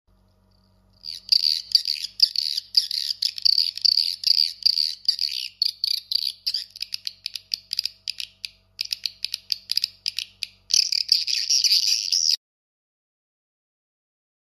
Swallow Sound SB 140 Piezo Tweeter sound effects free download